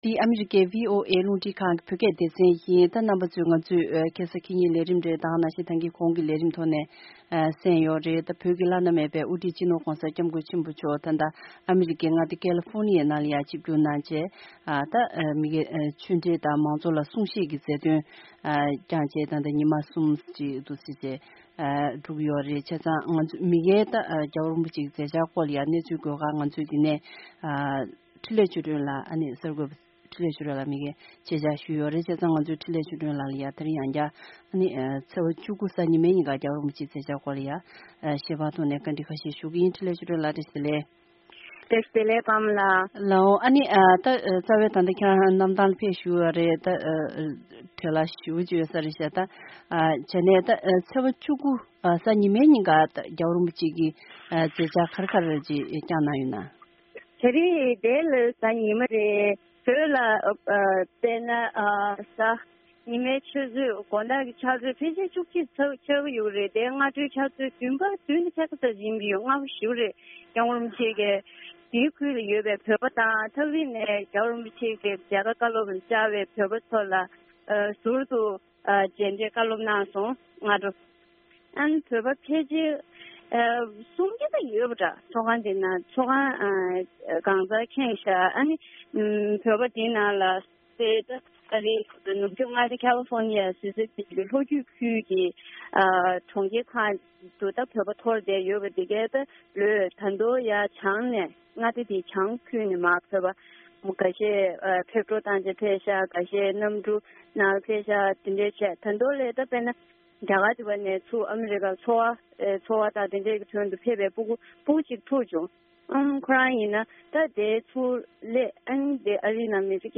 ས་གནས་ནས་སྤེལ་བའི་གནས་ཚུལ་ཞིག་གསན་གྱི་རེད།